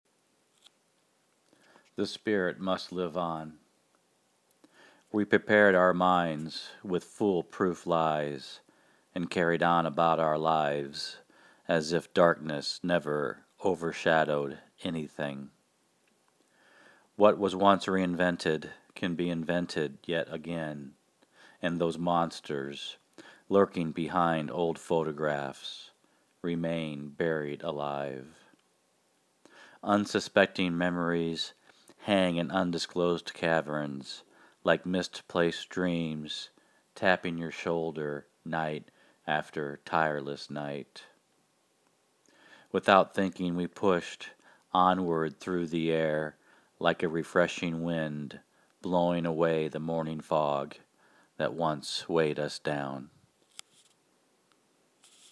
And I love hearing your reading.